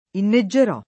vai all'elenco alfabetico delle voci ingrandisci il carattere 100% rimpicciolisci il carattere stampa invia tramite posta elettronica codividi su Facebook inneggiare v.; inneggio [ inn %JJ o ], ‑gi — fut. inneggerò [ inne JJ er 0+ ]